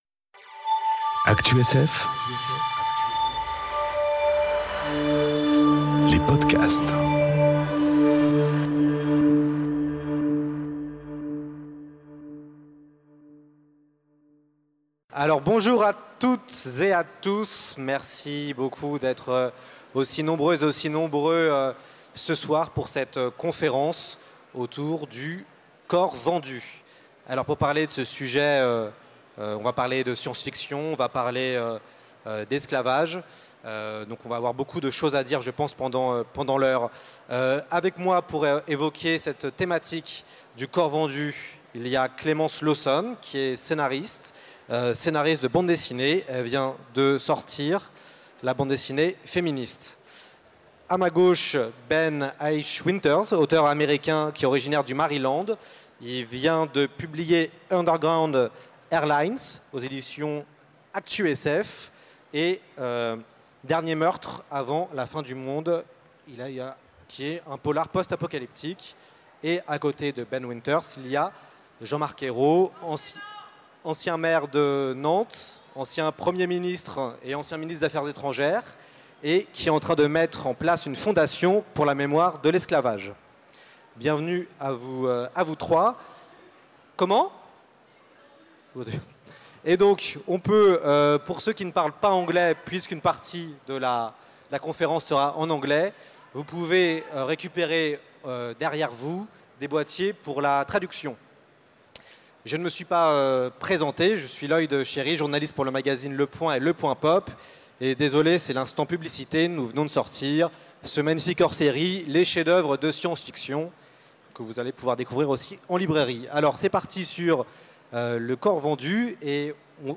Conférence Le corps vendu enregistrée aux Utopiales 2018